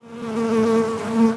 fly_buzz_flying_04.wav